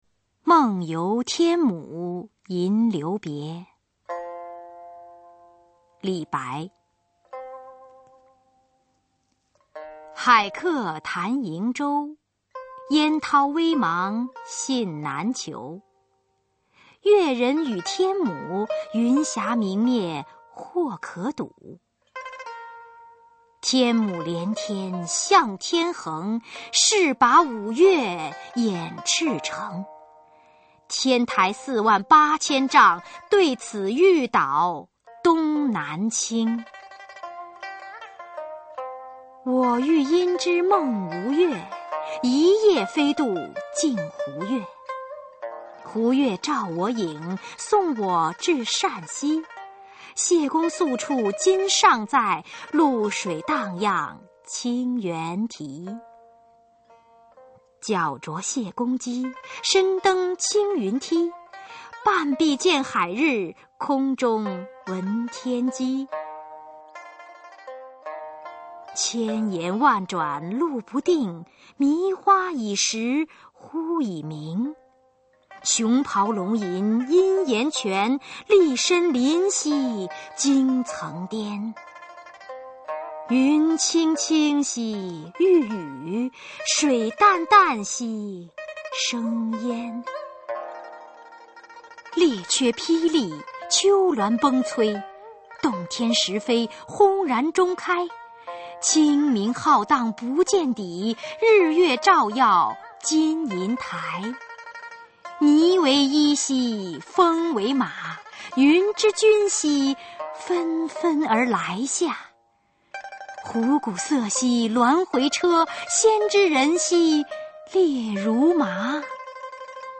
[隋唐诗词诵读]李白-梦游天姥吟留别（女） 唐诗吟诵